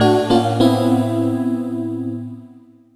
CHORD39 01-L.wav